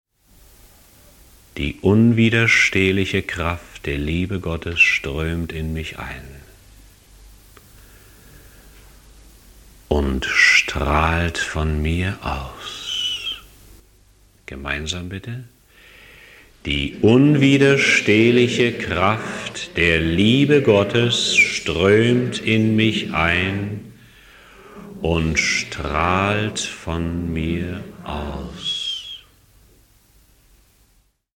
In einem Chakren-Seminar 1984